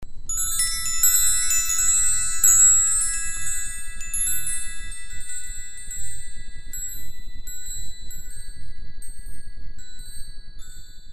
Finch Wind Chime
Sweetly rings out the pure musical notes of E, F#, G#, B, C#, E. The 6 solid 3/8"" hand tuned bells are made of a specially developed metal alloy similar to aircraft skin that will maintain their perfect pitch and never rust.